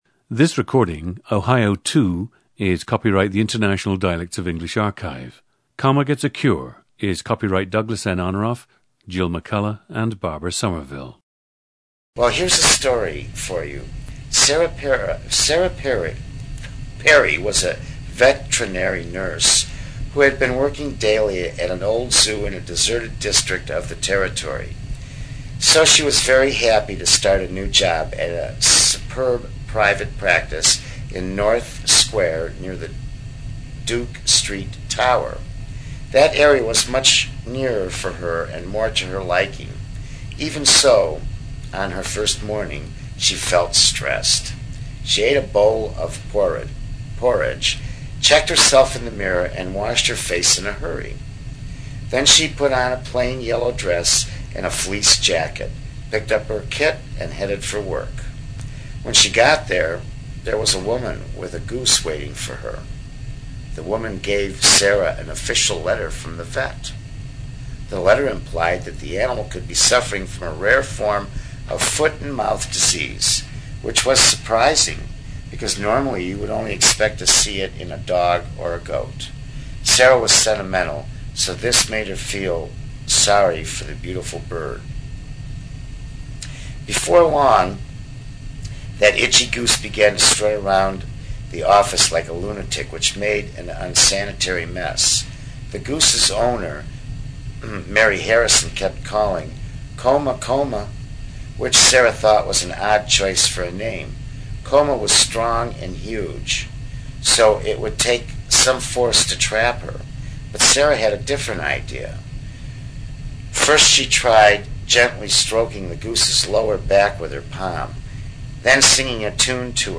GENDER: male
His speech is typical of the “north-midland” Ohio dialect (also see Ohio 1) but also includes characteristics that may trace back to his ethnic heritage, which is German-Polish.
His speech also has the nasality typical of this region and the hard or retroflexed /r/.
The recordings average four minutes in length and feature both the reading of one of two standard passages, and some unscripted speech.